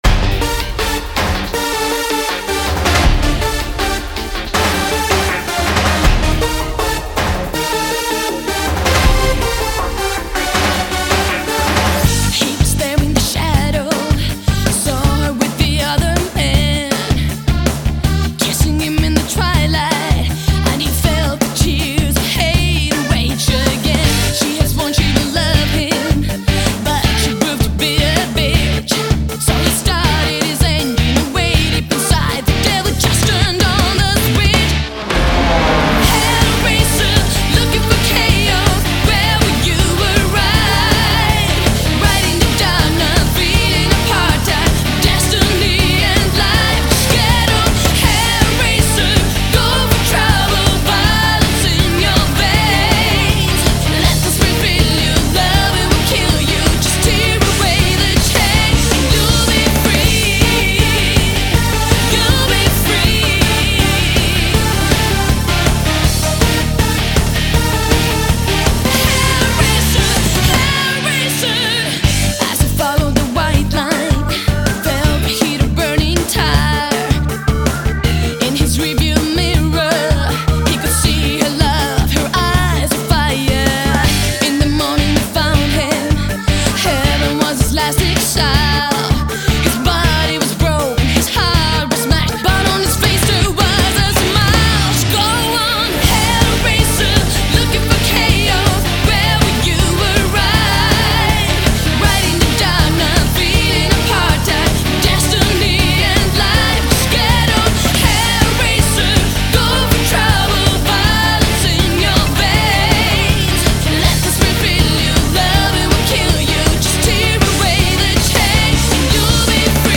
BPM160
Audio QualityPerfect (High Quality)
Full version of the song.